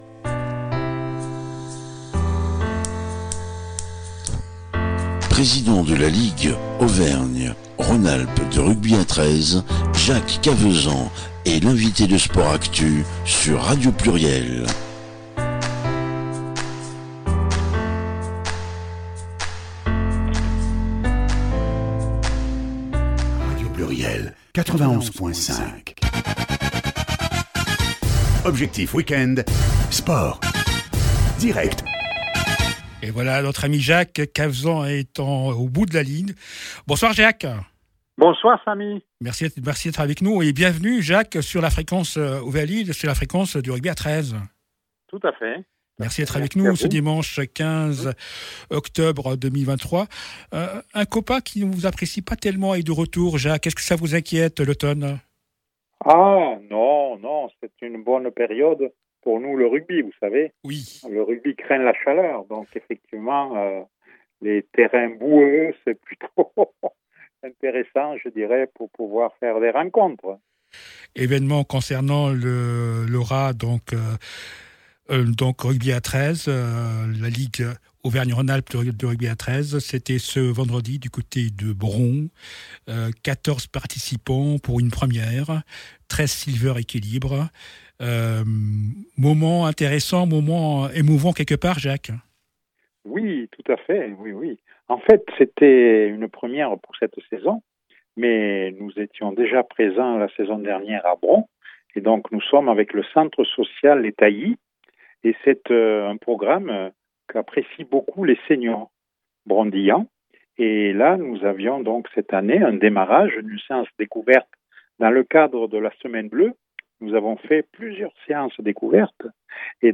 L’interview du président mois de JUILLET 2025